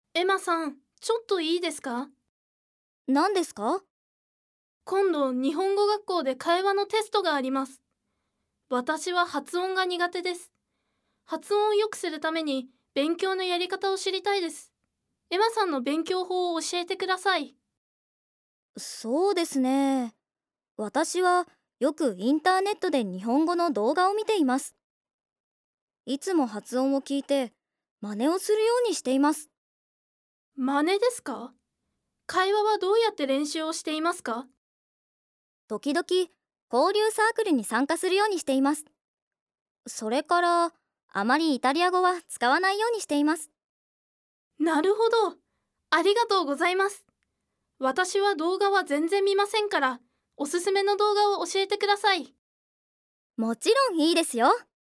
豊富な音声教材
96話の日常会話を繰り返し聞くことで、ナチュラルな会話スピードに慣れ、 日本語の語彙や表現を文字だけでなく音で覚えることができます。